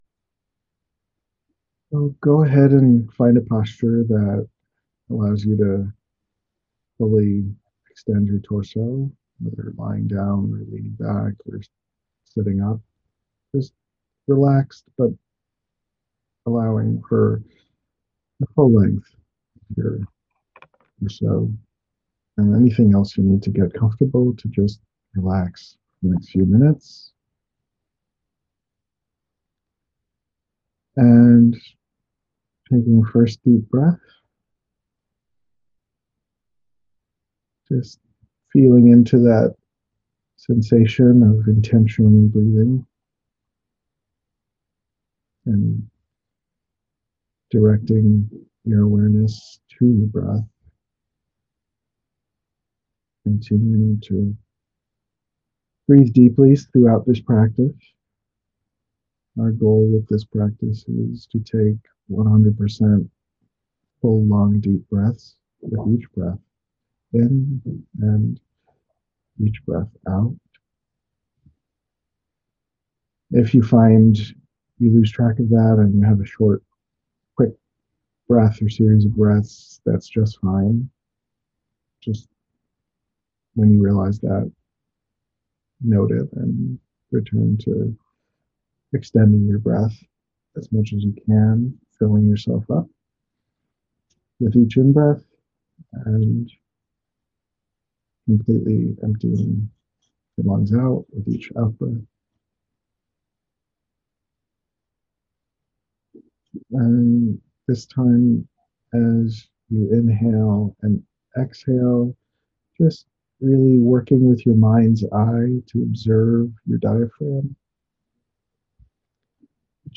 Guided deep breathing & intentional relaxation
Click here for a gentle, slow, 25-minute guided deep breathing and intentional relaxation audio that you can download now for free and play whenever you would like to develop these skills for yourself.